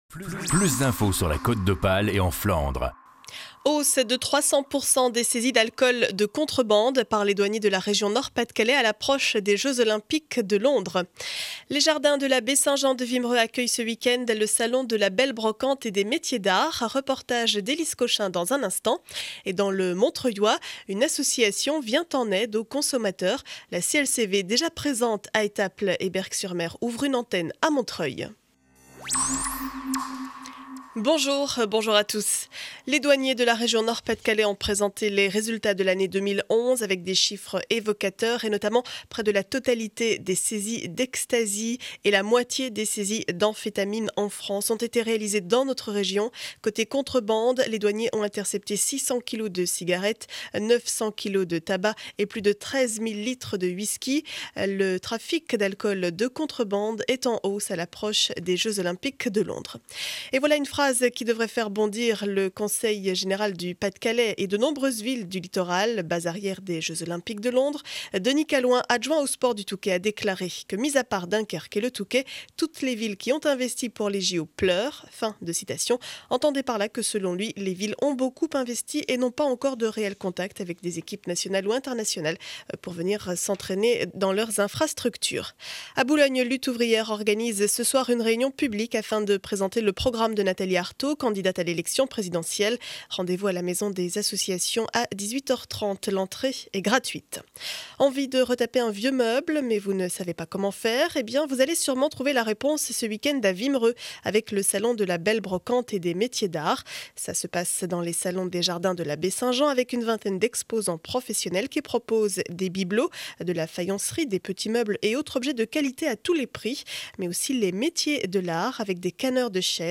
Journal du mercredi 22 février 2012 7 heures 30 édition du Boulonnais.